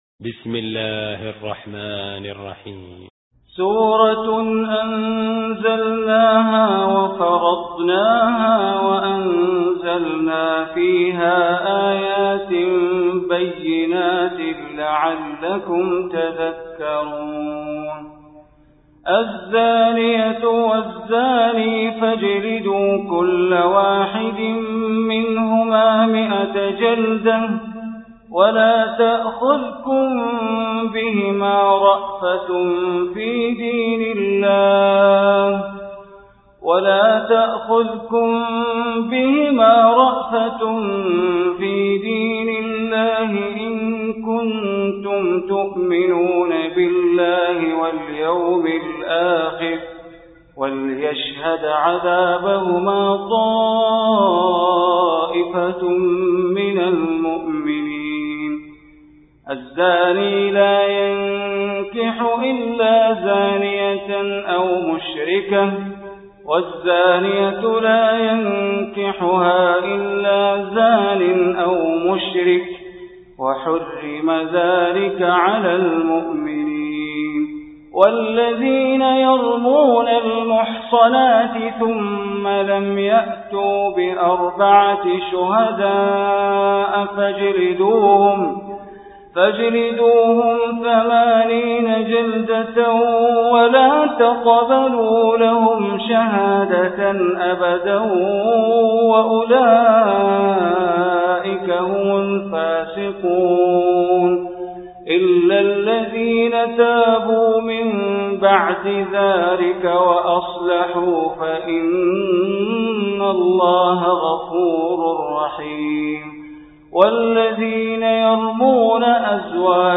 Surah Nur Recitation by Sheikh Bandar Baleela
Surah Nur, listen online mp3 tilawat / recitation in Arabic recited by Imam e Kaaba Sheikh Bandar Baleela.